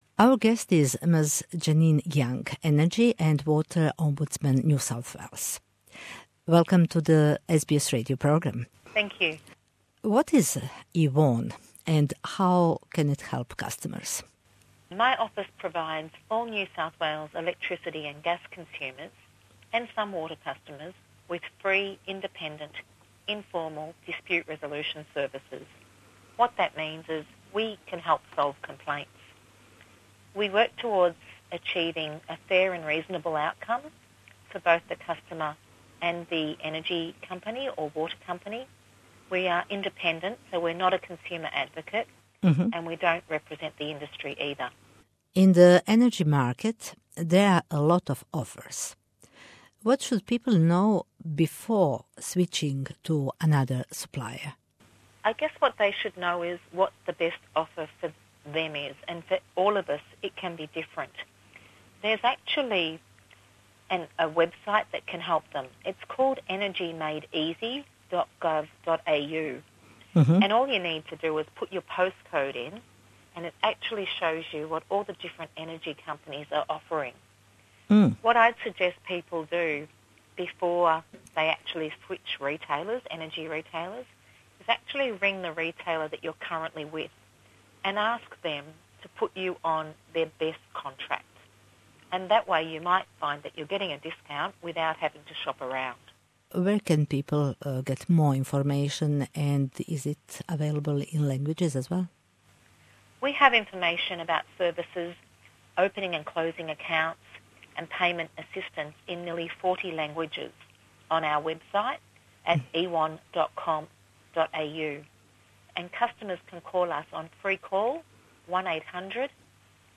Омбудсман за енергетске услуге и водоснабдевање у Новом Јужном Велсу (The Energy & Water Ombudsman NSW (EWON) пружа бесплатне, независне и фер услуге свим корисницима струје и воде у НЈВ, који желе решење спорова. Госпођа Ђенин Јанг (Ms Janine Young) је омбудсман за енергетске услуге и водоснабдевање и за СБС програм на српском објашњава детаљније које врсте бесплатних услуга су доступне заинтереованим потрошачима.